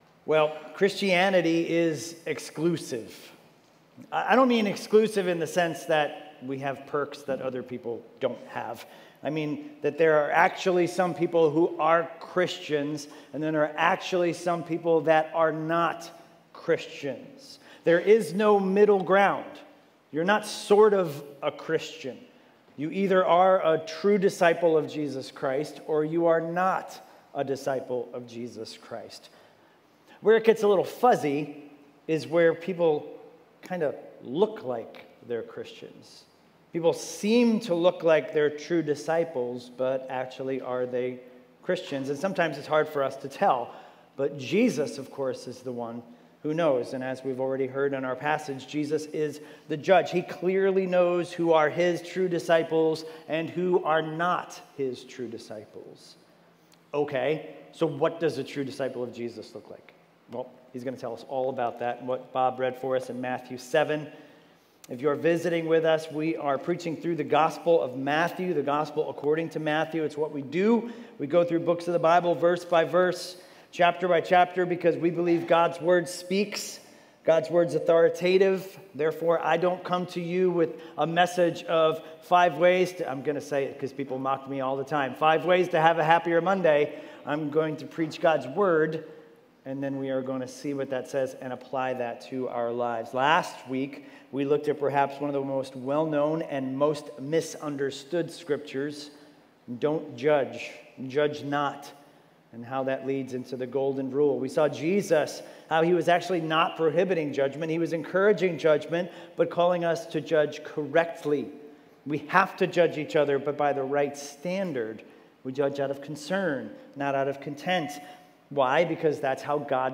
Expositional teaching series through the book of Matthew - starting Sunday, Dec 6, 2020